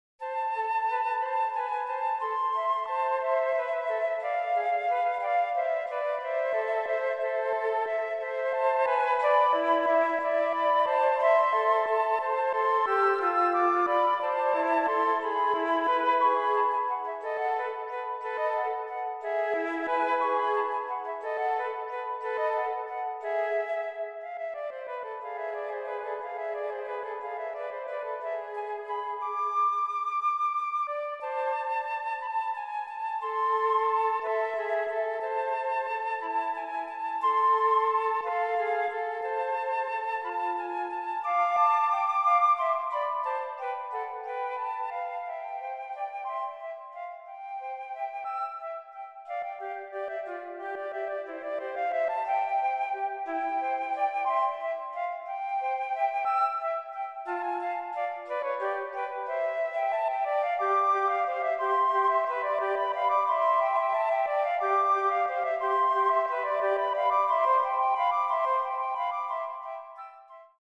für 2 Flöten
Allegretto